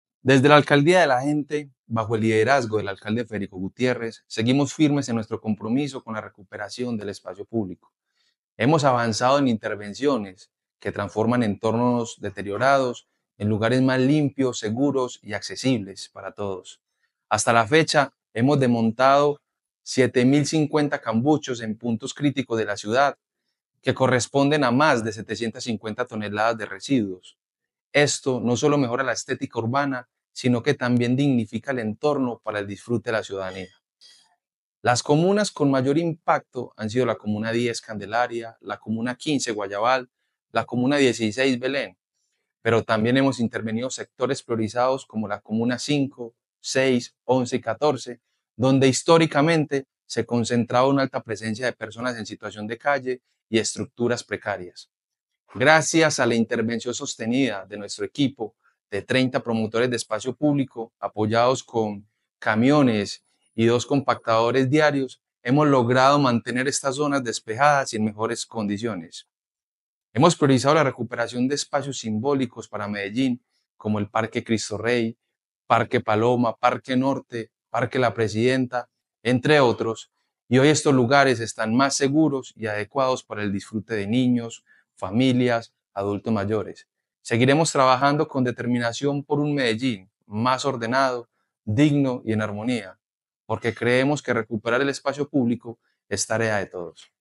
Audio-Declaraciones-del-subsecretario-de-Espacio-Publico-de-Medellin-David-Ramirez.mp3